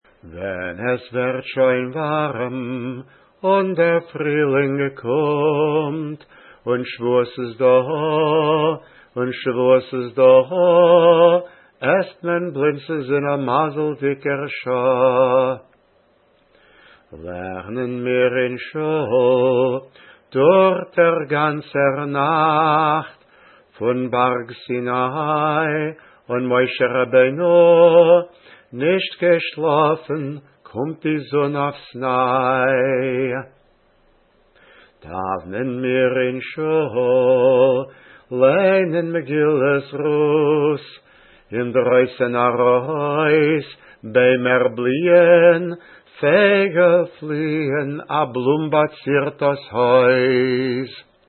tekst (PDF) muzik (PDF) rekordirung fun der varshtat